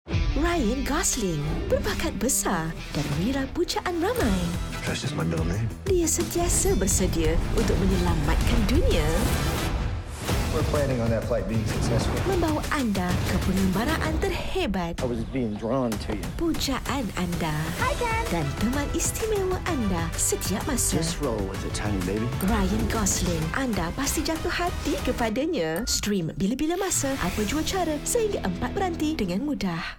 Female
Energetic Young Corporate Fatherly/Motherly High-pitched Low Gravelly Smooth Sexy Conversational
Ryan Gosling Specials Sweet & Cheerful